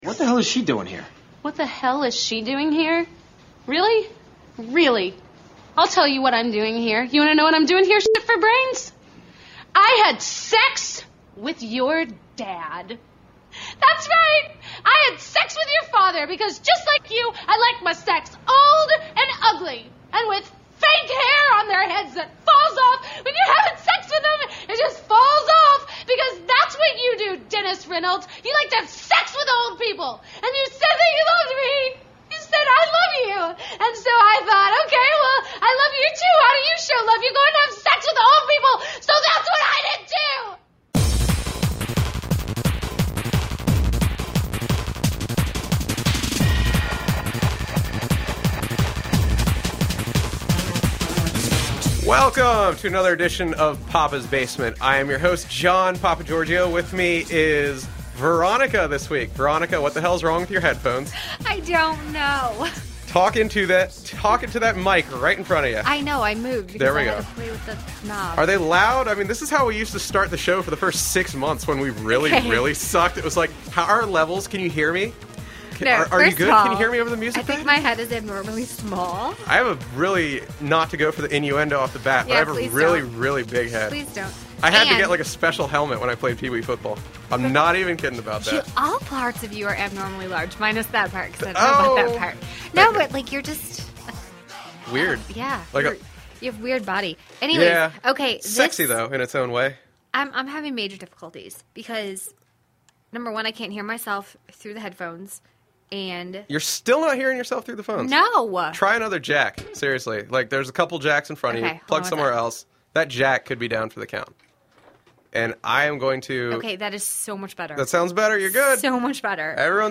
There’s also a priceless call from an old, befuddled, conservative listener.